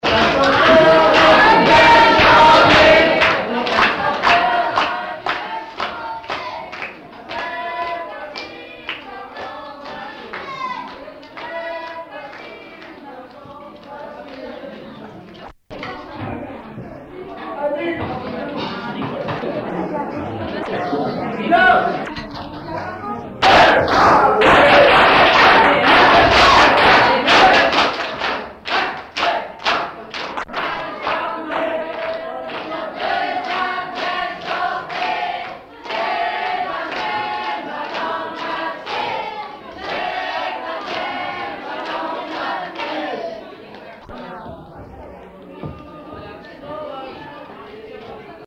Usage d'après l'analyste circonstance : fiançaille, noce ;
Genre brève
Catégorie Pièce musicale inédite